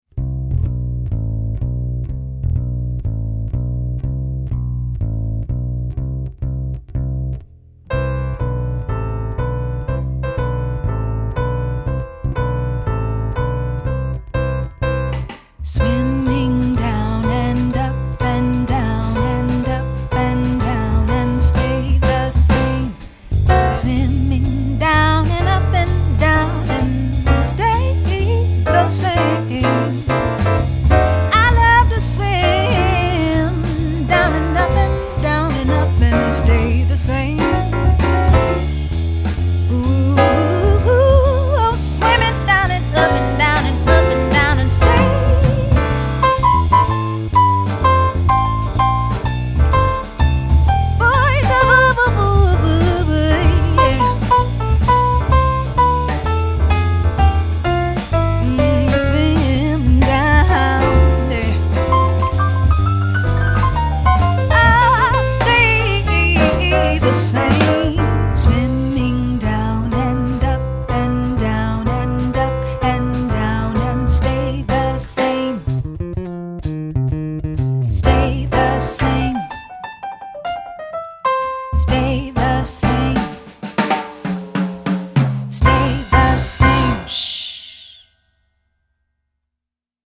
Just like the things that swim down and up in the water, the notes in this song go down and up too.
At The Piano
This song is played by the LEFT hand.